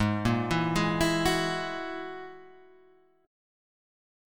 Ab7sus2#5 chord